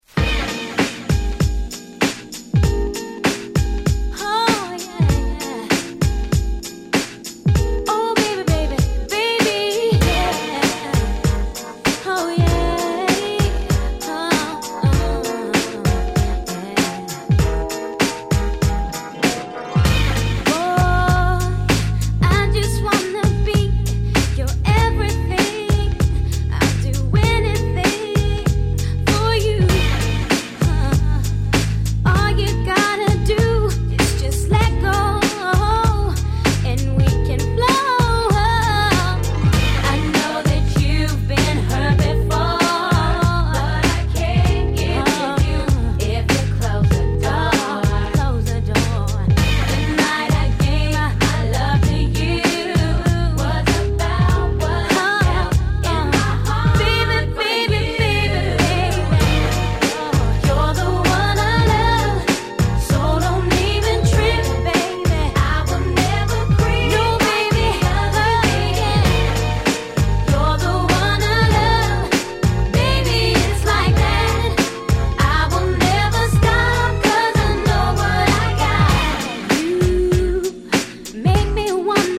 96' Nice UK R&B !!
切ないメロディーに彼女の透明感溢れるVocalが気持ち良すぎるUK R&B名曲中の名曲！